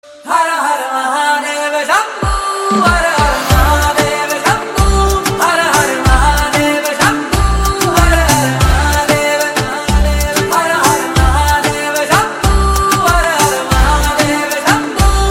CategoryDevotional / Bhakti